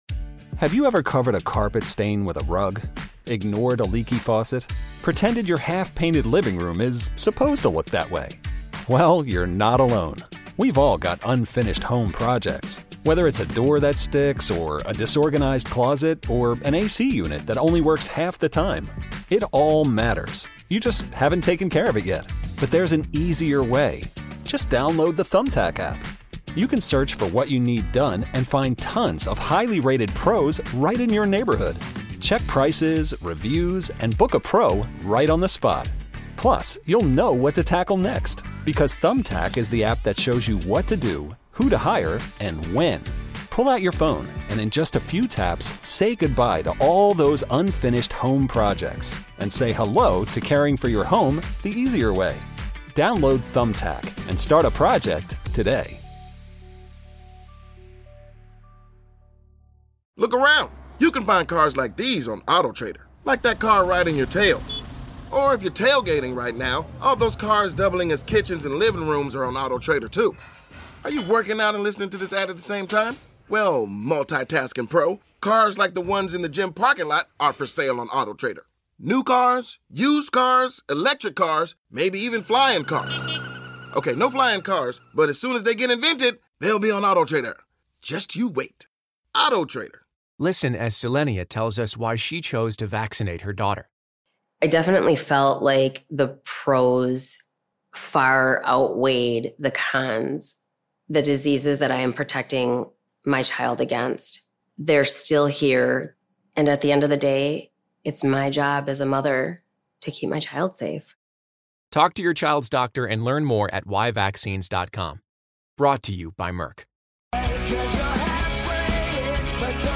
Should Joey Chestnut be allowed to compete in the Nathan's Hot Dog Eating Contest? | A WILD fact about Gen Z you won't believe | Your phone calls.